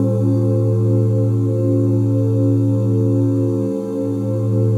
OOHASHARP9.wav